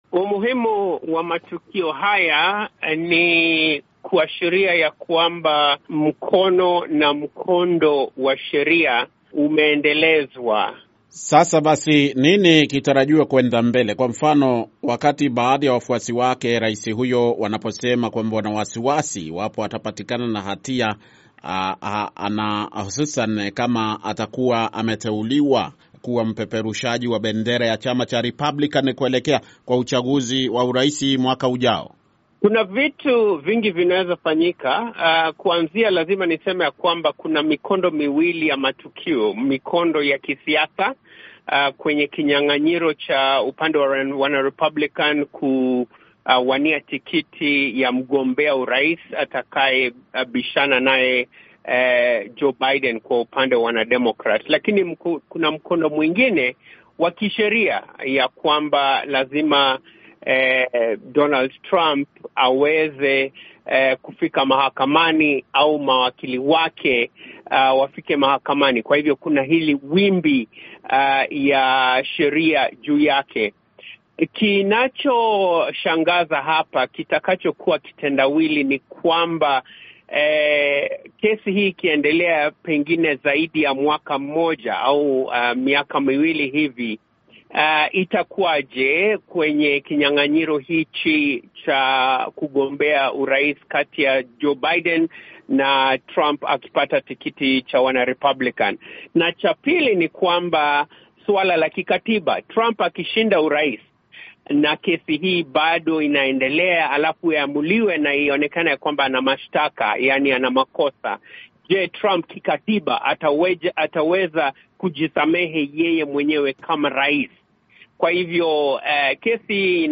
VOA Swahili imezungumza na mchambuzi wa siasa za Marekani